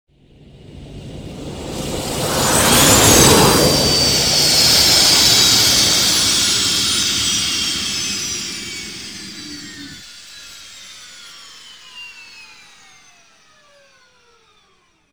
OtherLanding3.wav